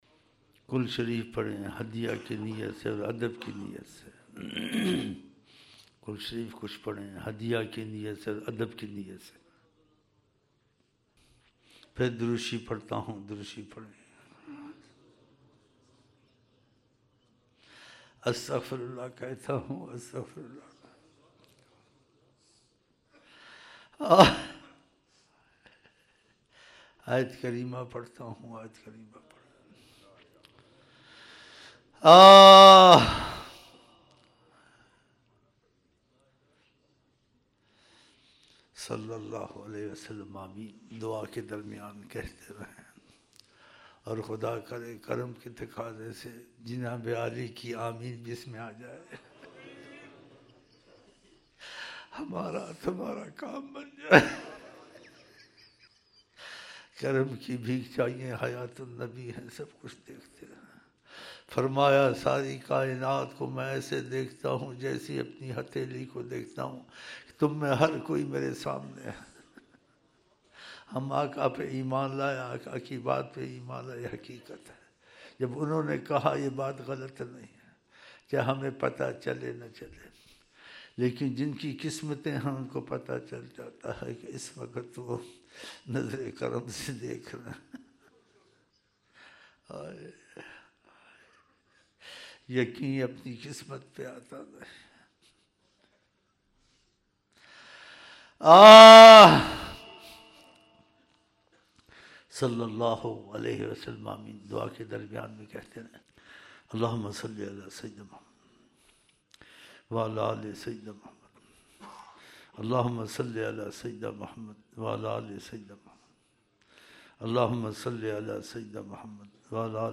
Dua 1 January 2006 Fajar Mehfil